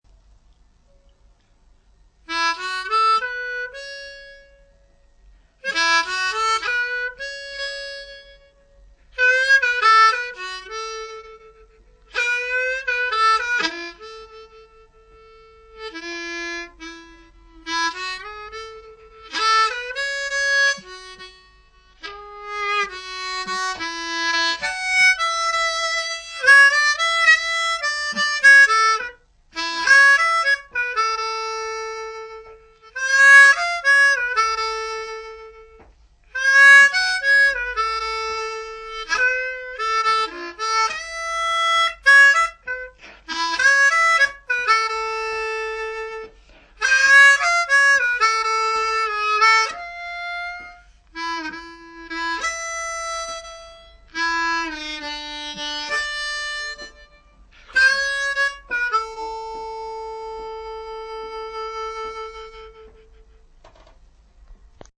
ゲロゲロムズイです。。。。
2ndポジション（曲KeyはＡ）